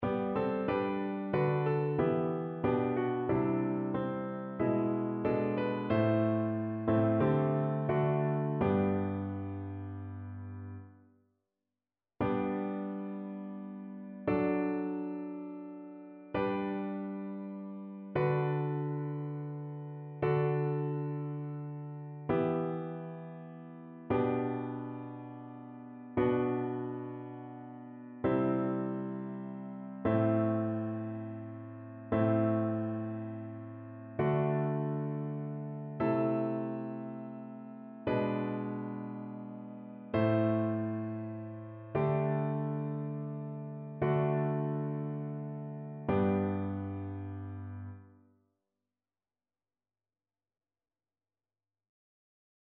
annee-b-temps-pascal-2e-dimanche-psaume-117-satb.mp3